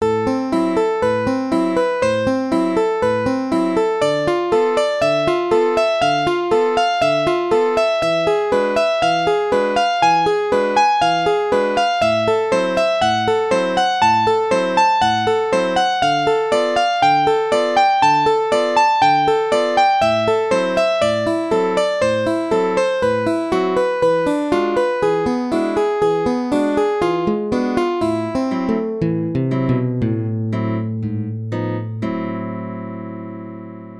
I purposely often use 6th and 7th arpeggios because it sounds gypsy jazz when you use them.
Technique of gypsy jazz
So picking goes ↑↓↓↓　↑↓↓↓